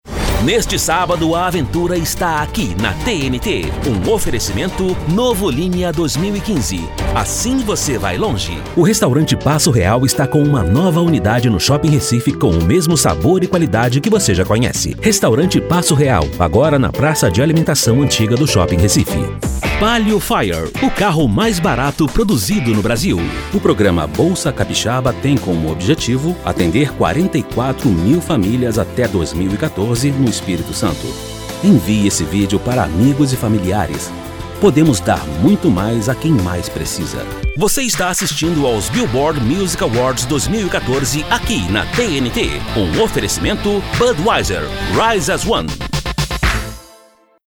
PT BR GC COM 01 Commercials Male Portuguese(Brazilian)